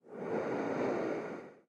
Звуки противогаза
Звук одного вздоха